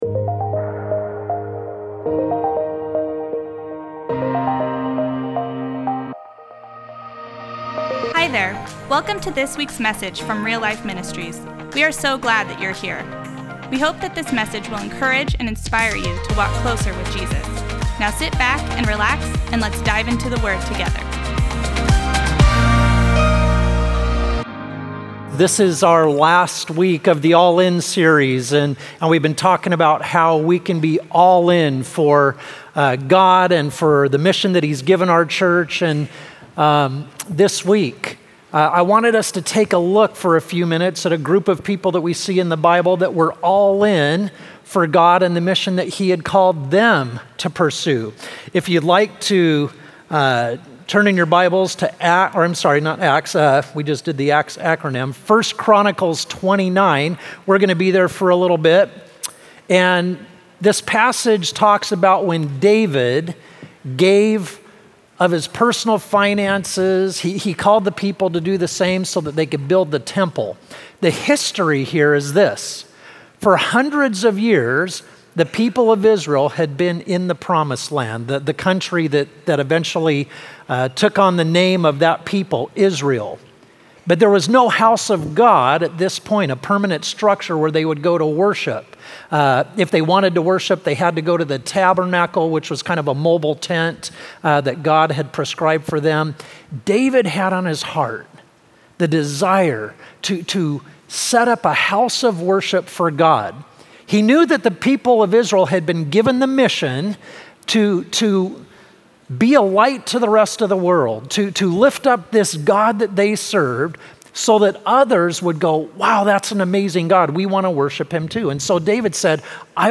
1. What about the sermon resonated with you?